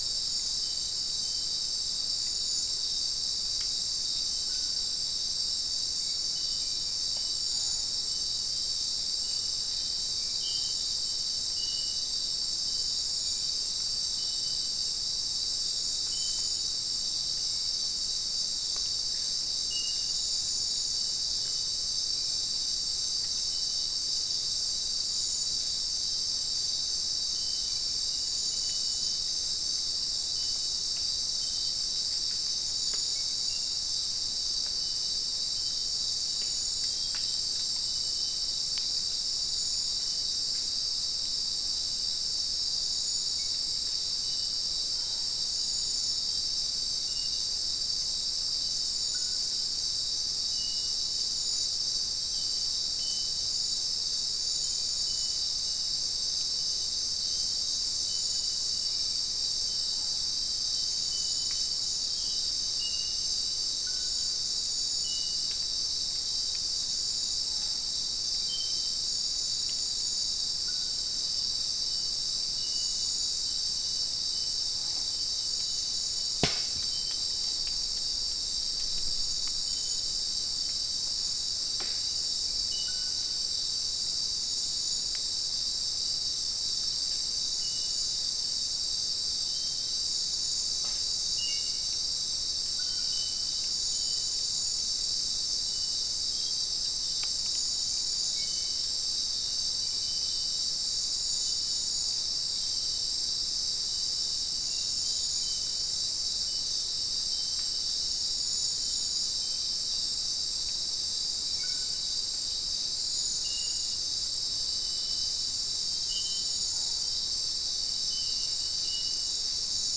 Non-specimen recording: Soundscape
Location: South America: Guyana: Kabocalli: 1
Recorder: SM3